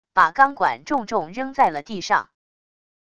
把钢管重重扔在了地上wav音频